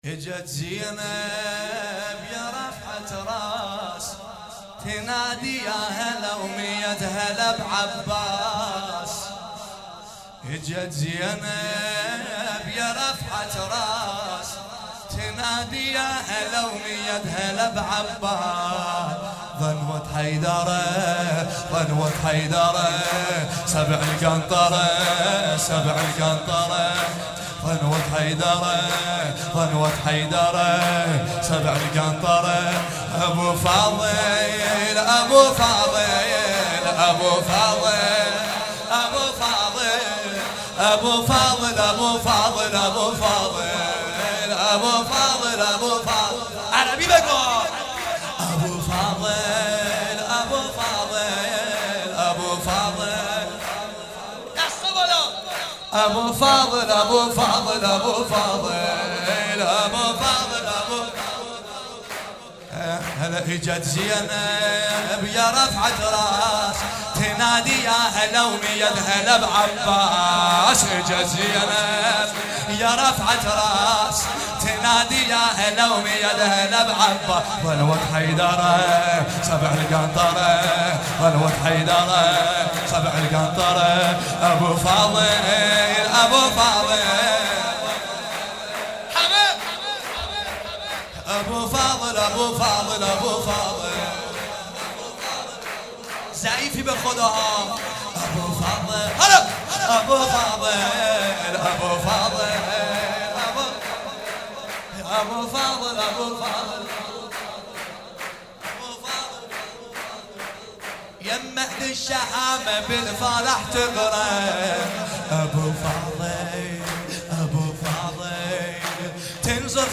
حسینیه حضرت زینب (سلام الله علیها)
سرود عربی